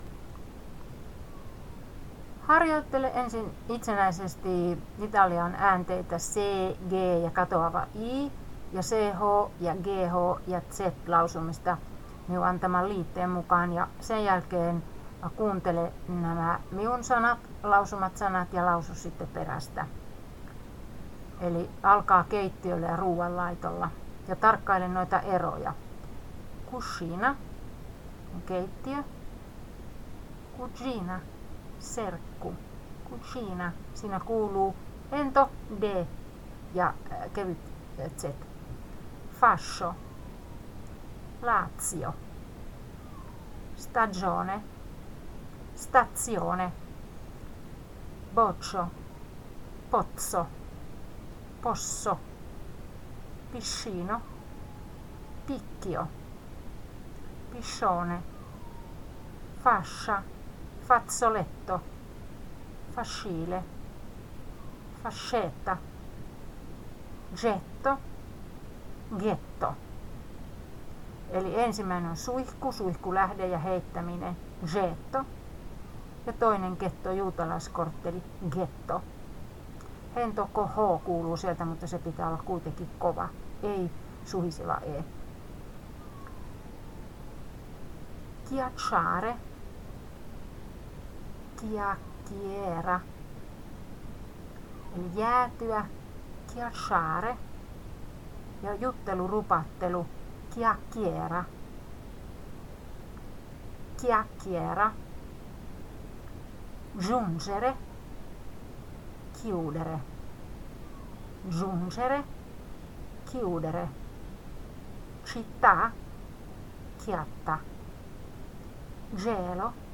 Ääntäminen - C, G, katoava i, Z.mp3